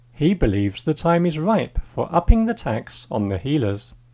DICTATION 3